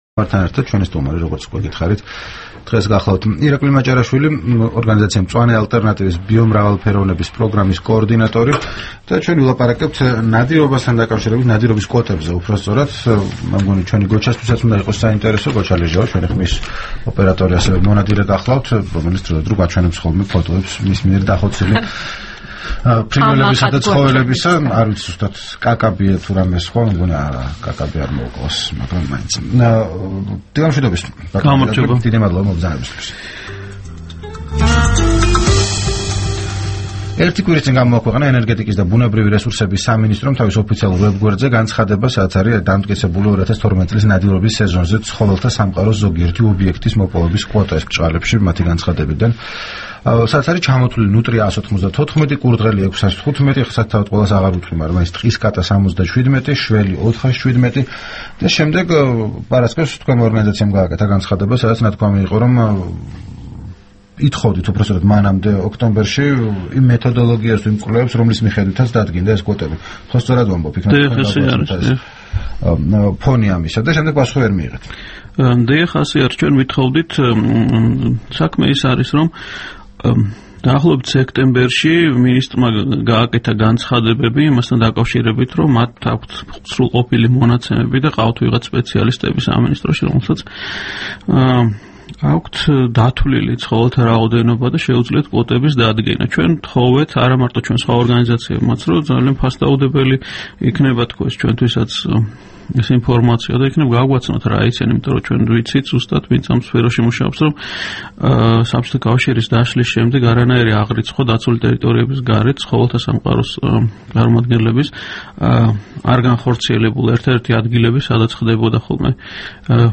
რადიო თავისუფლების თბილისის სტუდიაში სტუმრად იყო
საუბარი